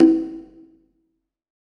HCONGAHIOP.wav